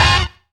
NOISE GTR.wav